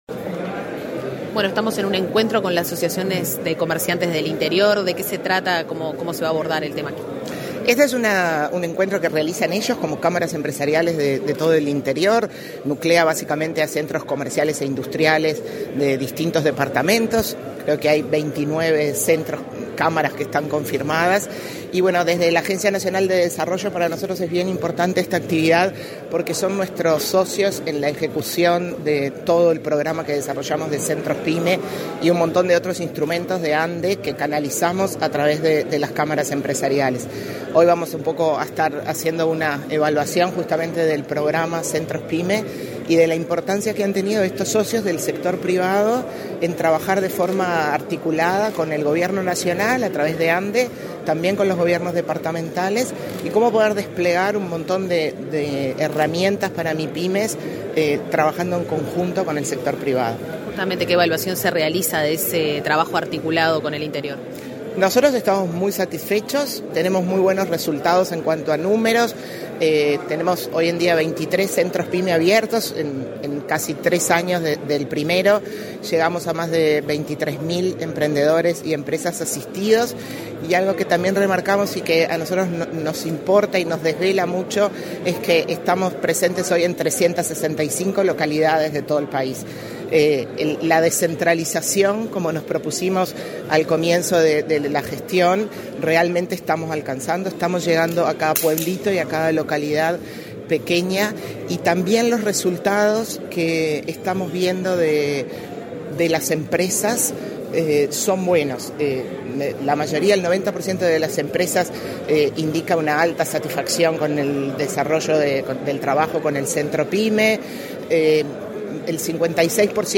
Entrevista a la presidenta de la ANDE, Carmen Sánchez
Con la participación del presidente de la República, Luis Lacalle Pou, este 21 de junio, se realizó el encuentro de asociaciones y centros comerciales del interior del país, en Paso de los Toros, departamento de Tacuarembó. Antes del evento, la presidenta de la Agencia Nacional de Desarrollo (ANDE), Carmen Sánchez, realizó declaraciones a Comunicación Presidencial.